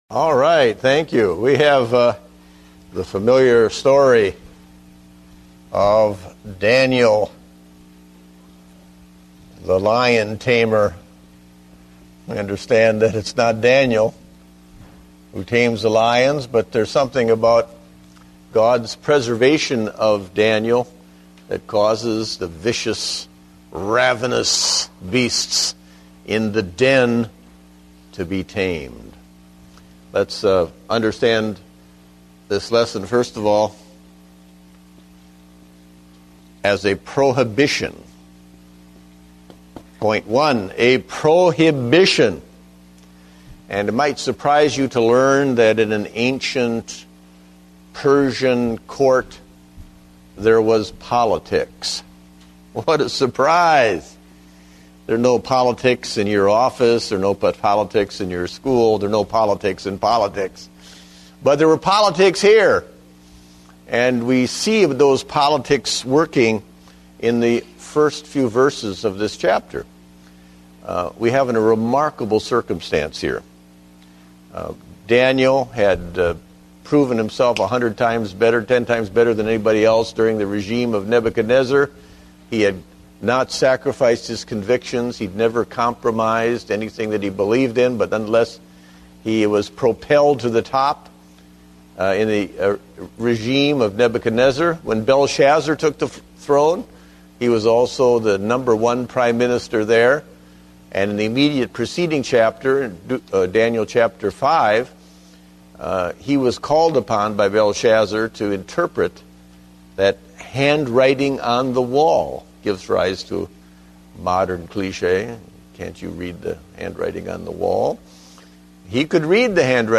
Date: December 27, 2009 (Adult Sunday School)